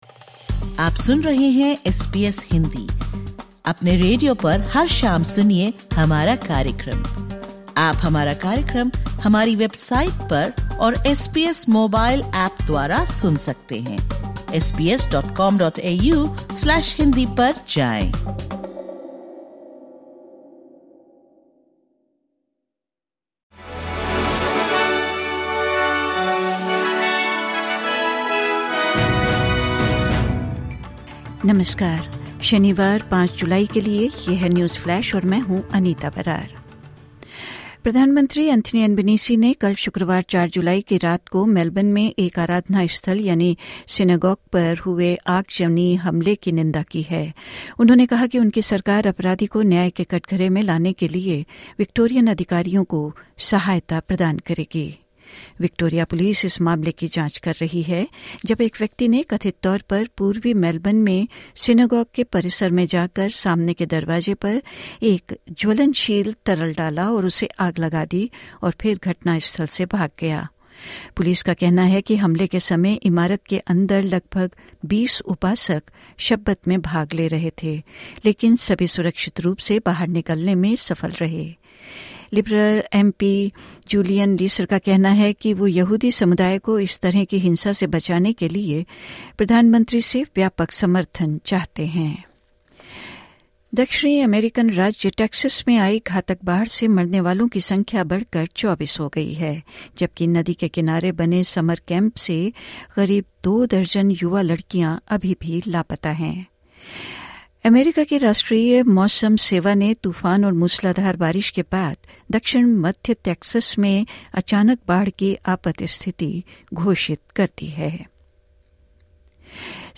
Listen to the top News of 28/06/2025 from Australia in Hindi.